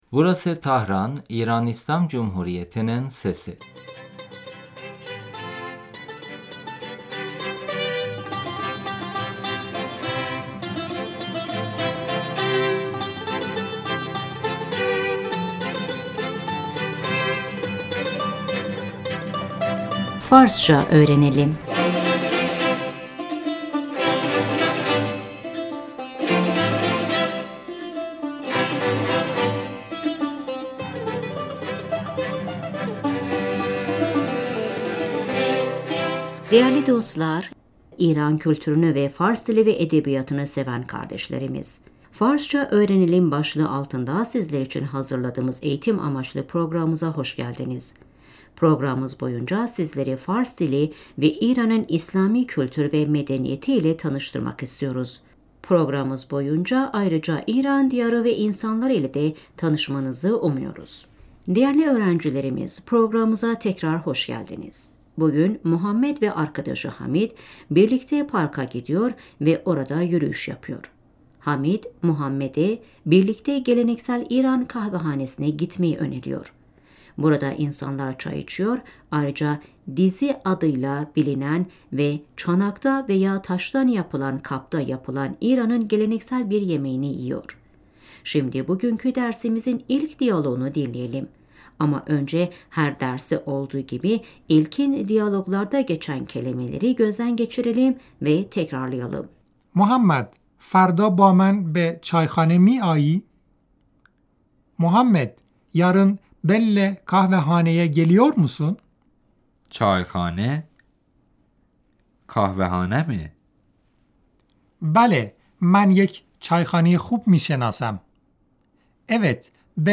صدایپرندگاندرپارکوصدایچندرهگذرازدور Parkta kuş sesi, ayak sesi حمید - محمد،فردابامنبهچایخانهمیآیی؟